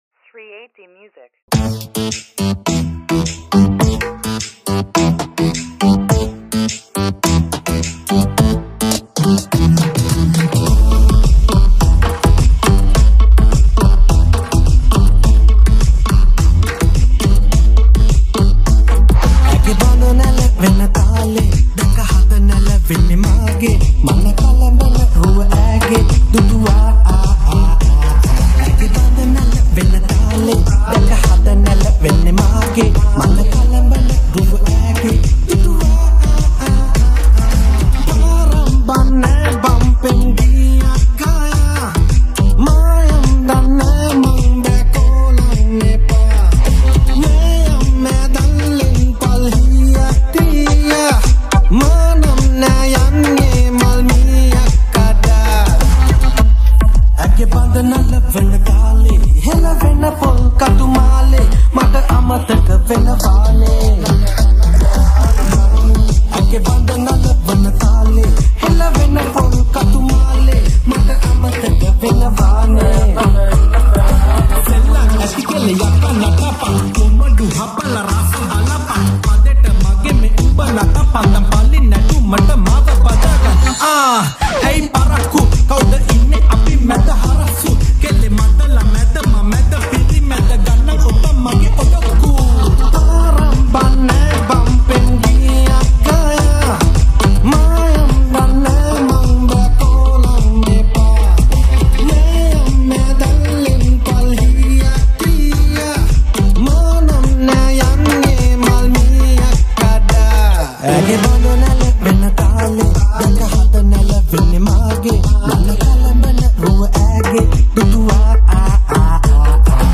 Sri 8D Music New Song